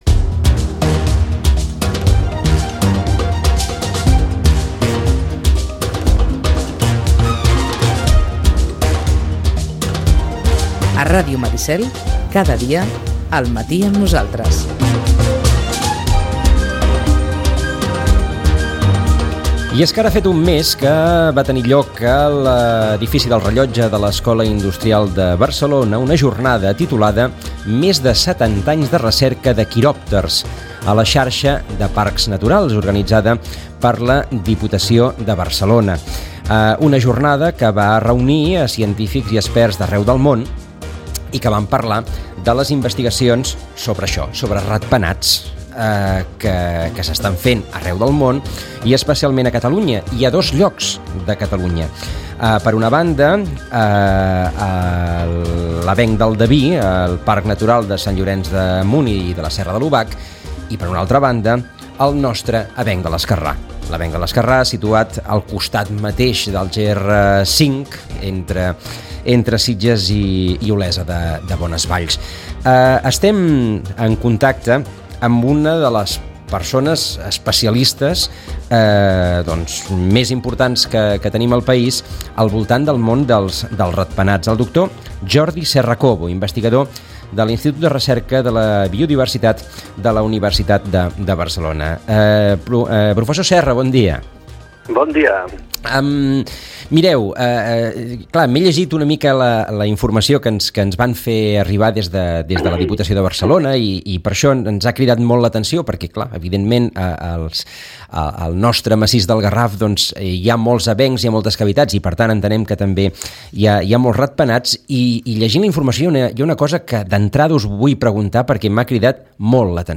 Ràdio Maricel. Emissora municipal de Sitges. 107.8FM. Escolta Sitges.